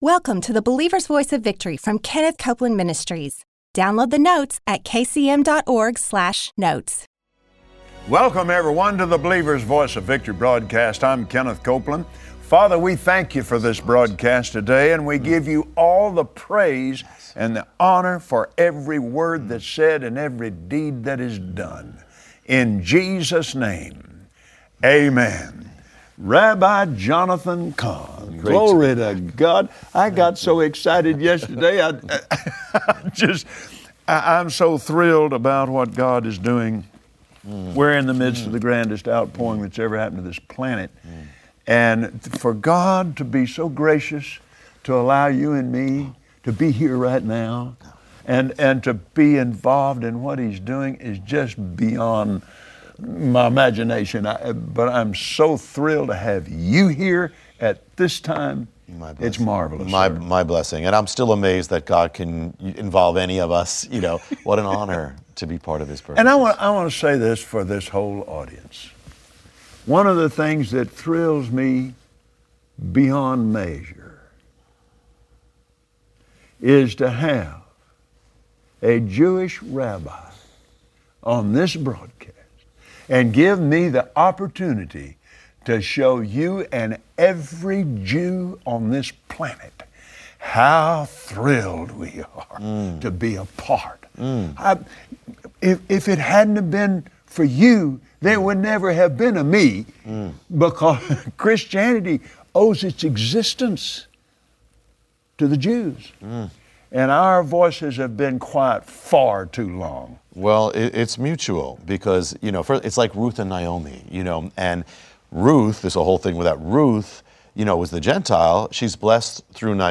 Find out today on the Believer’s Voice of Victory, as Kenneth Copeland and his guest, Rabbi Jonathan Cahn, reveal how the patterns of defiance from centuries ago, have reappeared today.